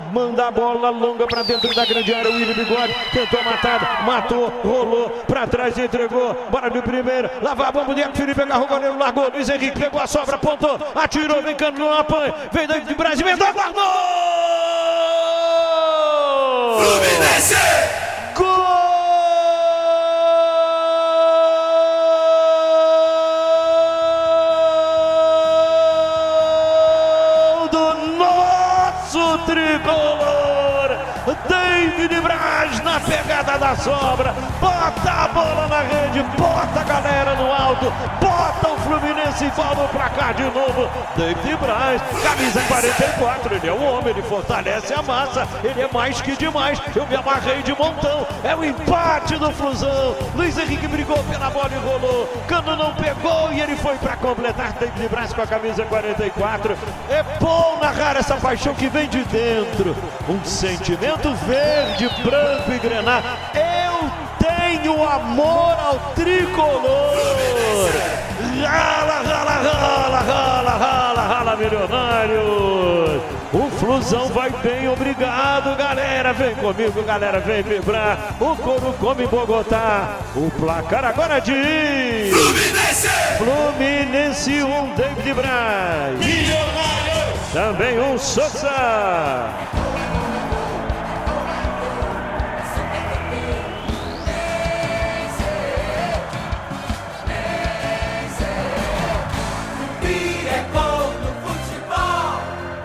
David Braz e Cano marcaram nos 2 a 1, em Bogotá, na Colômbia